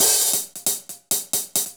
Index of /musicradar/ultimate-hihat-samples/135bpm
UHH_AcoustiHatA_135-04.wav